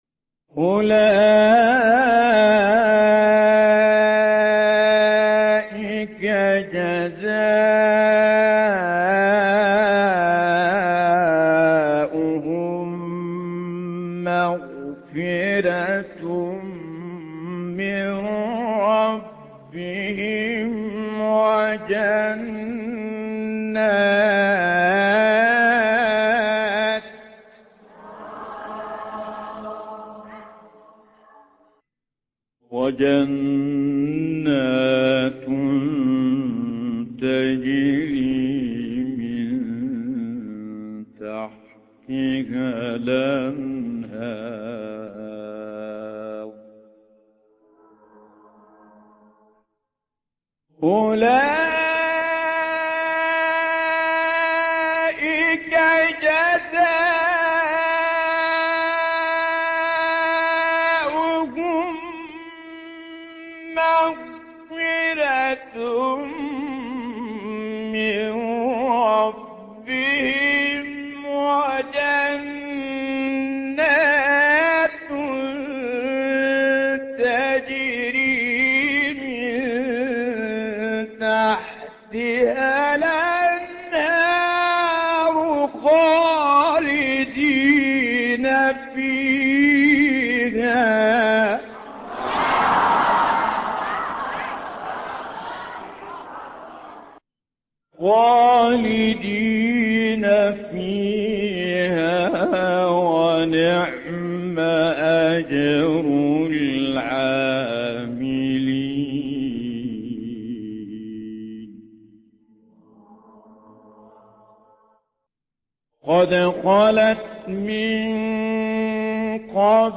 نغمة الصبا